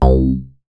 MoogHiVoc 008.WAV